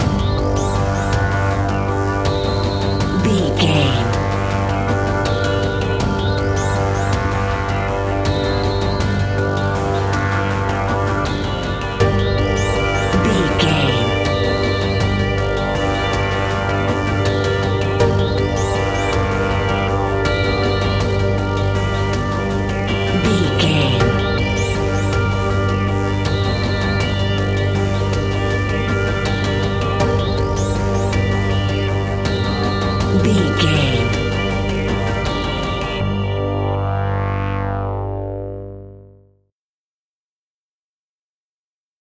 Aeolian/Minor
scary
ominous
dark
suspense
haunting
eerie
synthesiser
drums
ticking
electronic music
electronic instrumentals